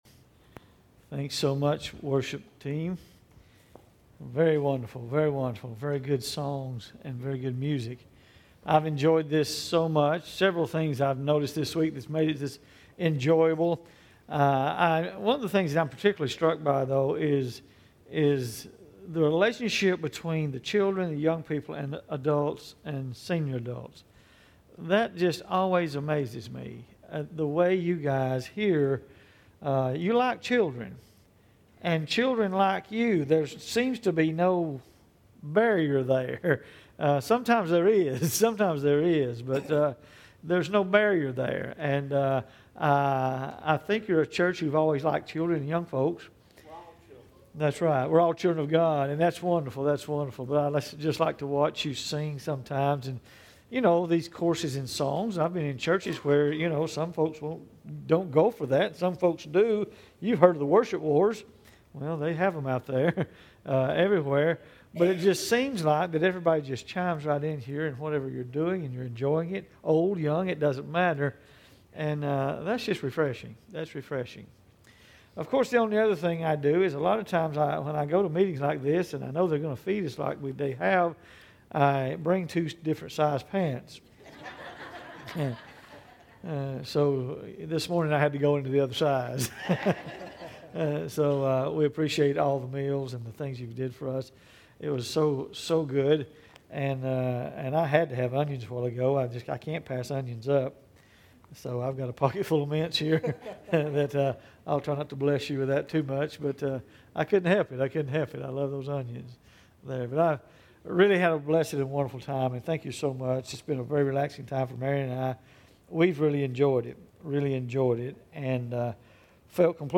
Chevis Oaks Baptist Church Sermons